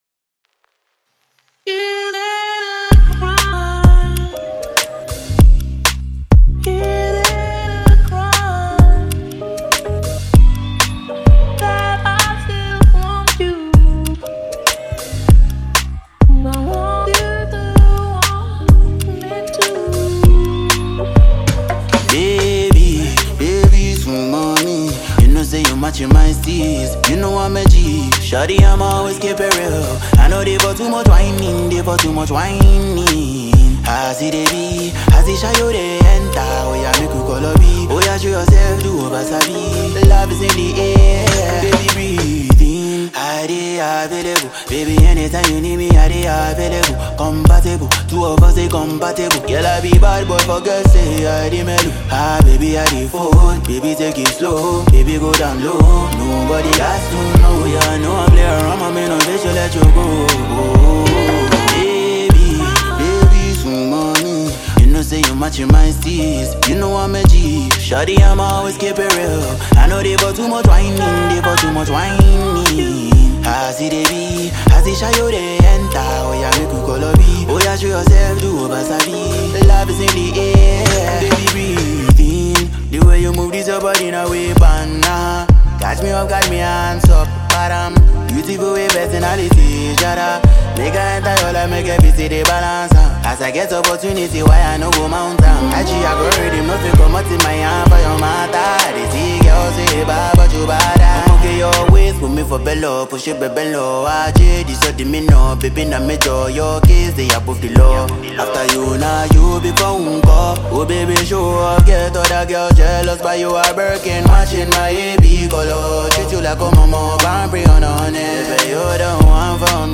Nigerian musical singer
You will always remember this song’s wonderful melody.